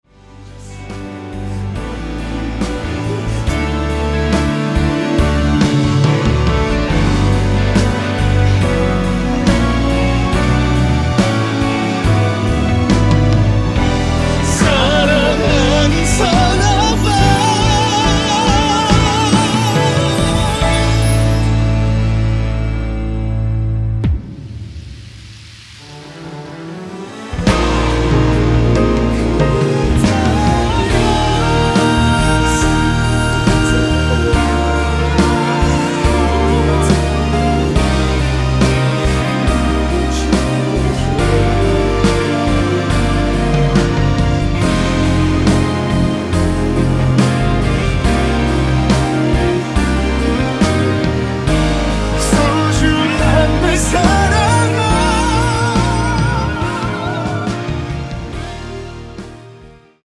원키에서(-1)내린 코러스 포함된 MR입니다.
앞부분30초, 뒷부분30초씩 편집해서 올려 드리고 있습니다.
중간에 음이 끈어지고 다시 나오는 이유는